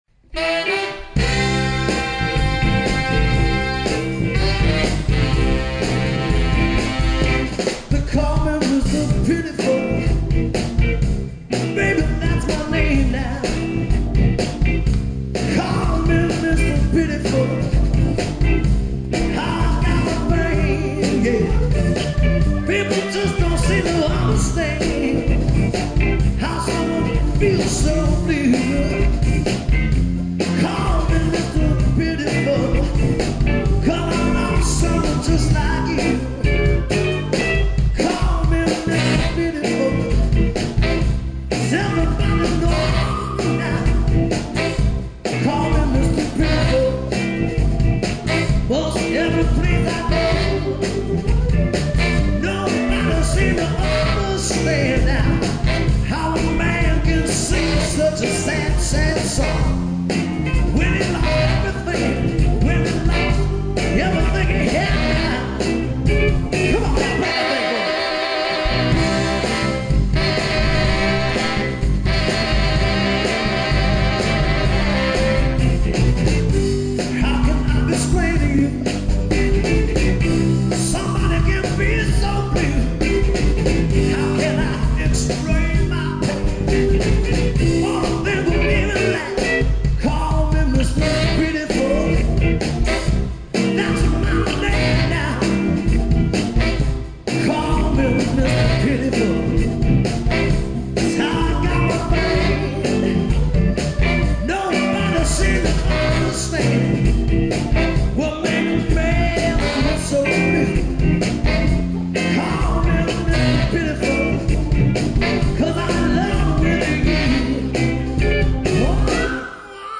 R&B, Soul, and Funk<
Roger Sherman Baldwin Park  - Greenwich, CT
( 2 trk live recording)